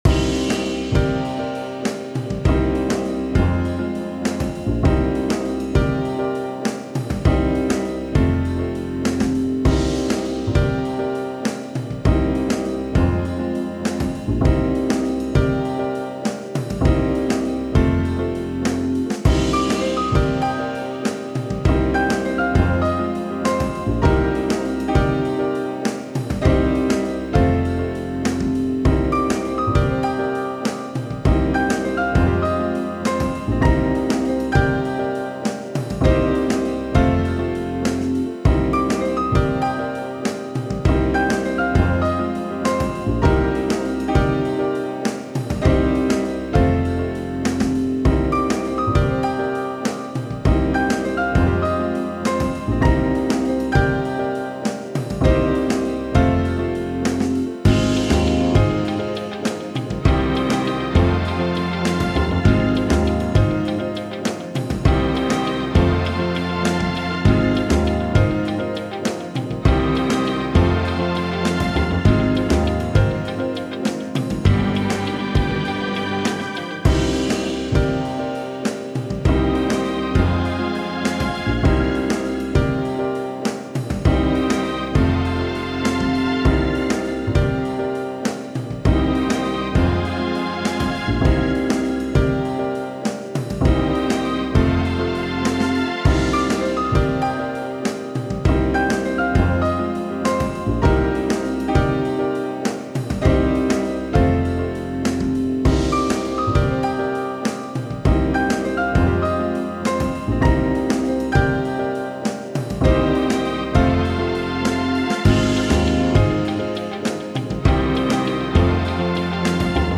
Hard Jazz on a mission